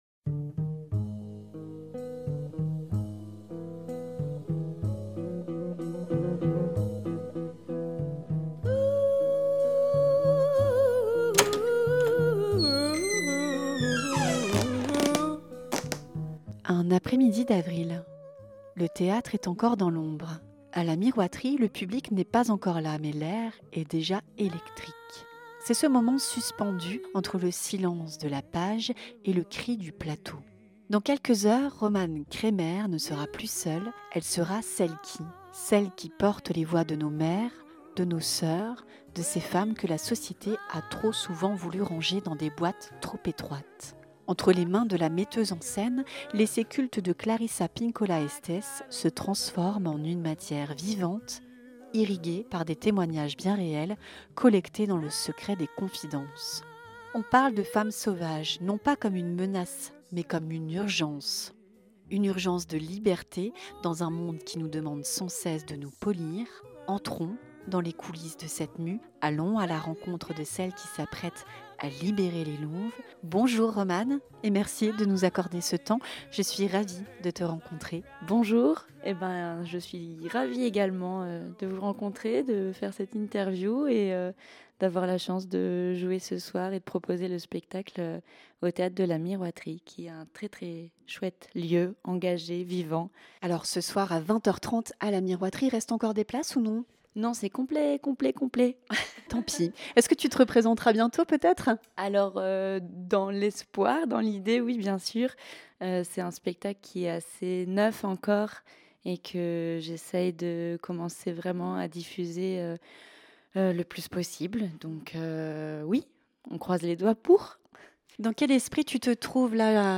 dans les coulisses du Théâtre de la Miroiterie ...